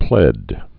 (plĕd)